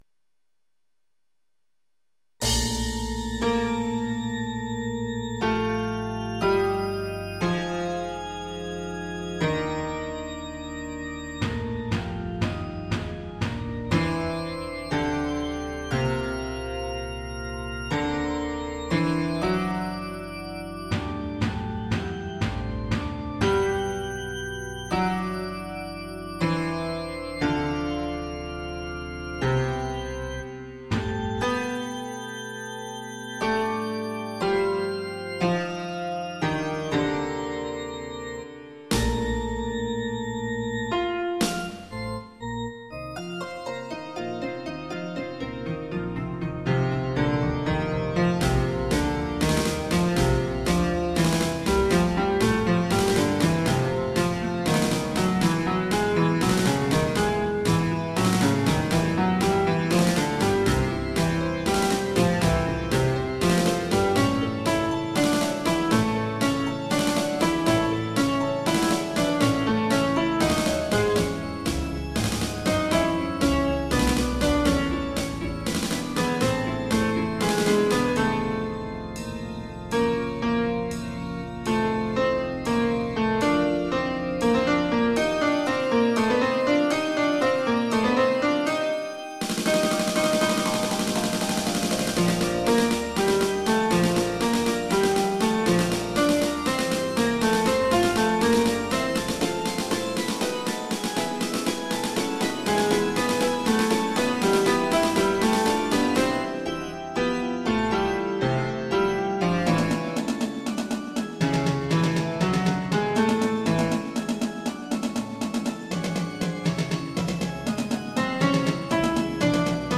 Basse